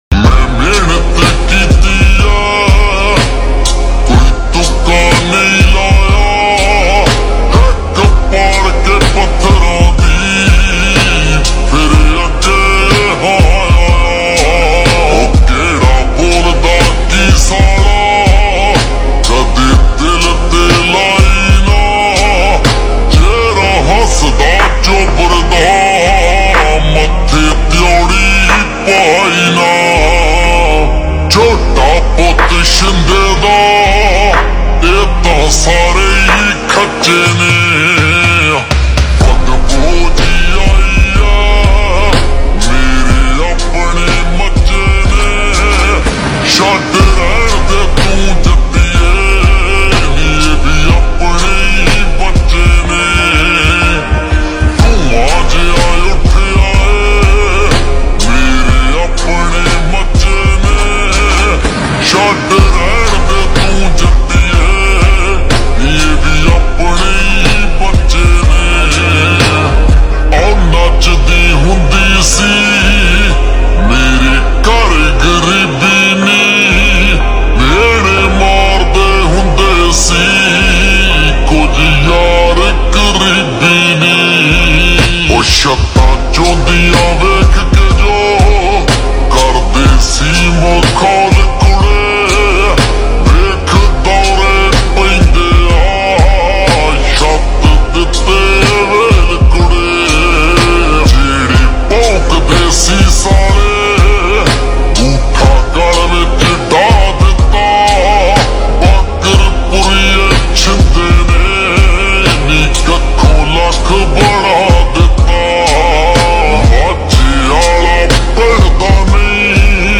FULL SONG SLOW X REVERB